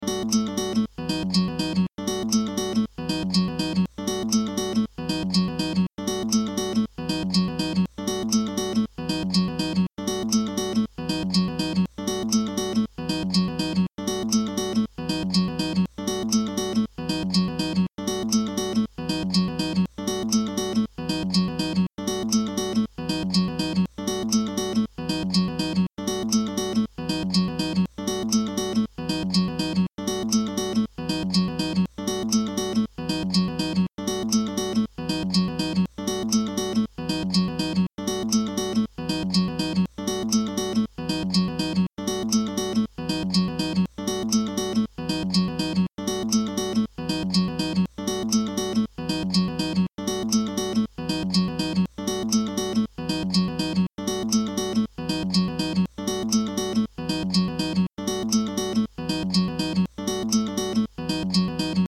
ダンス、エレクトロニカ、テクノ、ヒップホップ